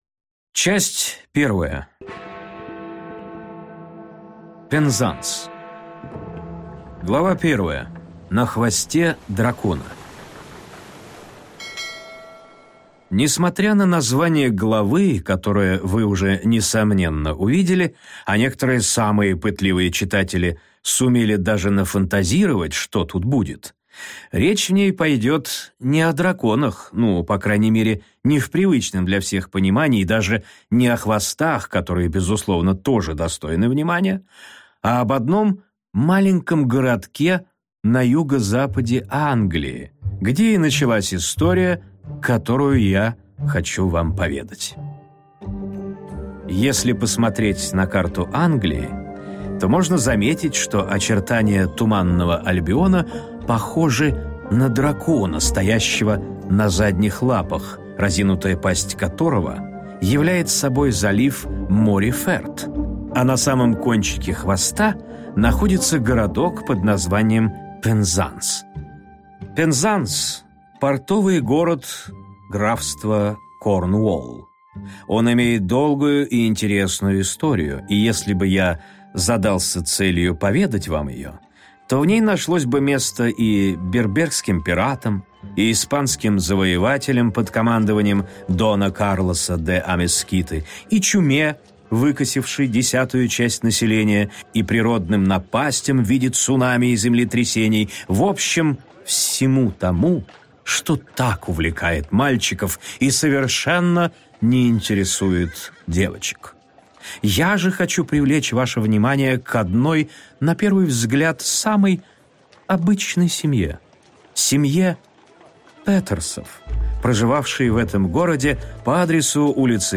Аудиокнига Петтерсы. Дети океанов | Библиотека аудиокниг